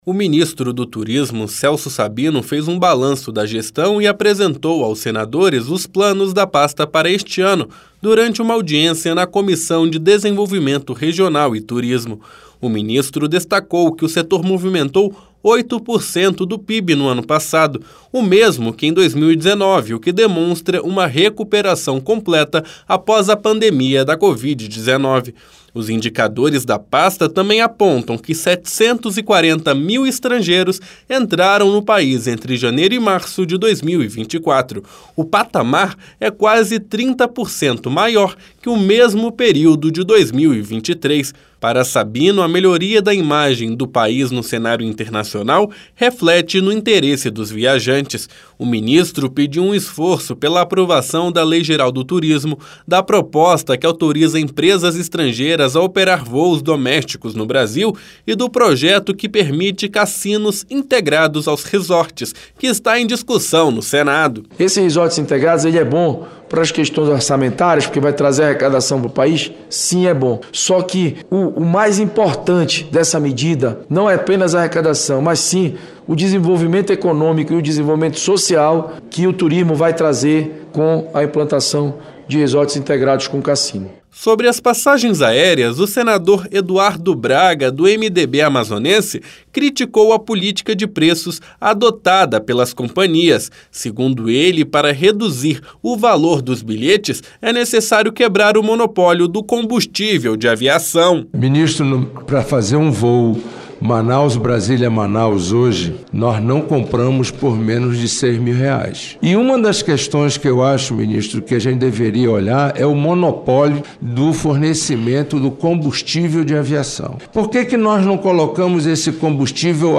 Audiência pública